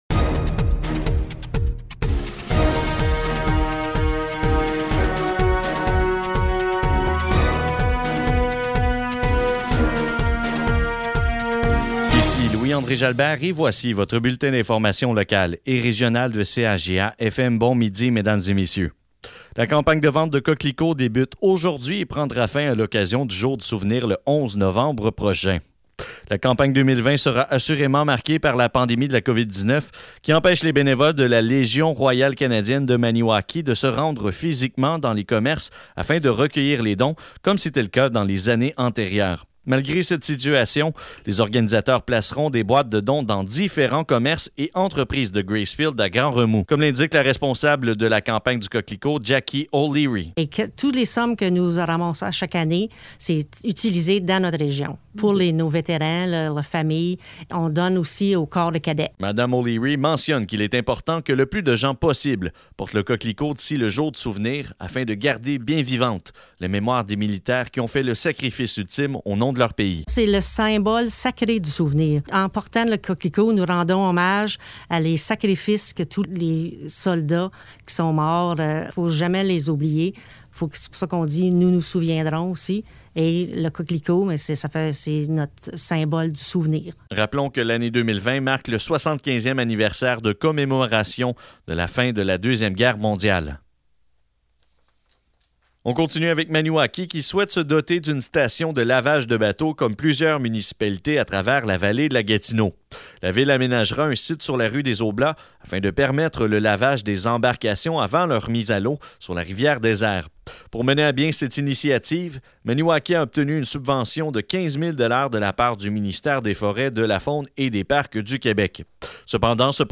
Bulletins de nouvelles